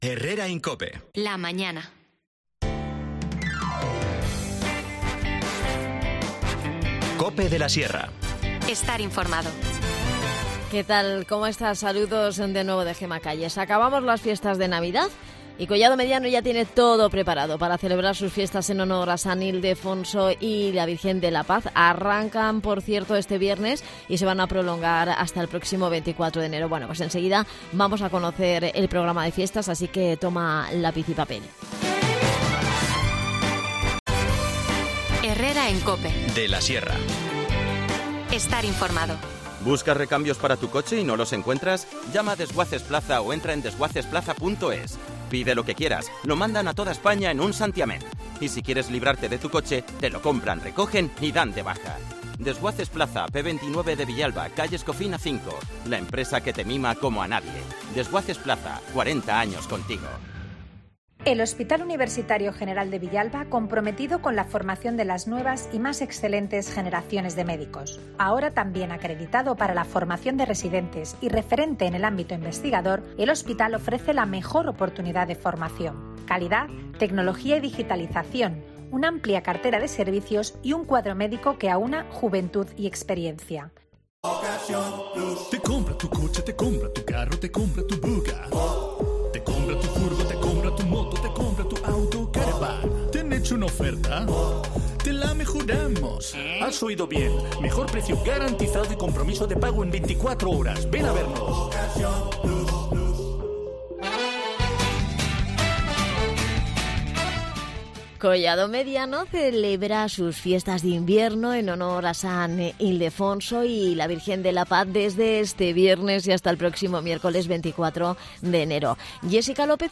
Charlamos con Yésica López, concejal de Festejos.